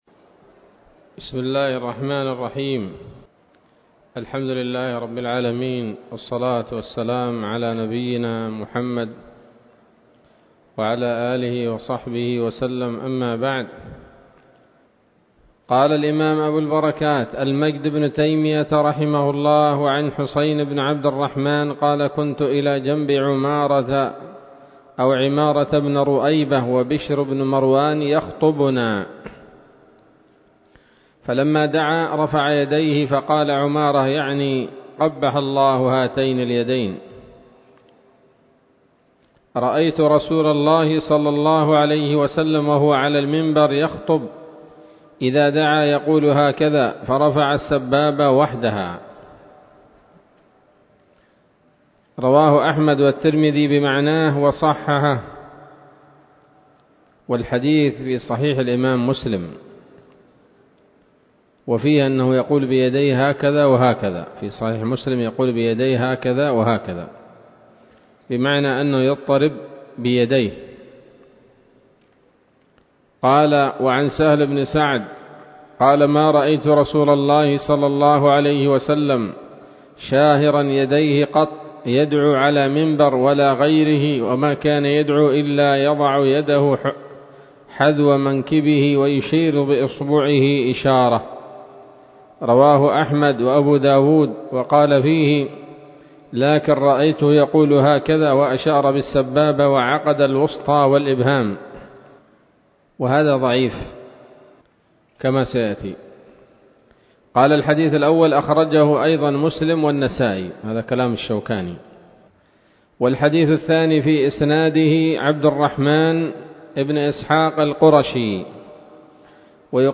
الدرس الثلاثون من ‌‌‌‌أَبْوَاب الجمعة من نيل الأوطار